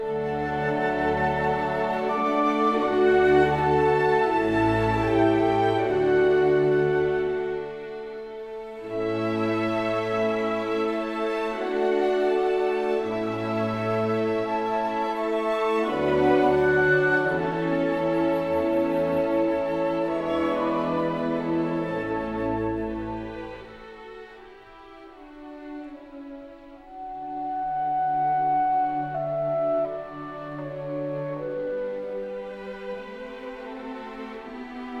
Instrumental Classical
Жанр: Классика / Инструмантальные